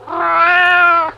meow2.wav